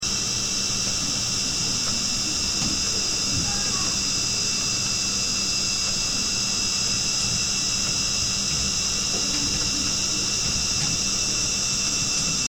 fort bruit de rotation d'un dd
j'ai acheté un dd d'occasion, un ibm de 4go environ, et il m'inquiète un peu car il fait un bruit très fort de rotation. je l'ai formaté pour y mettre des données et ça a marché nickel, mais je me demande si c'est normal qu'il soit si bruyant  :heink:
avec le micro j'ai enregistré le son en question:
(en réalité le son est un peu + grave le micro a déformé le son)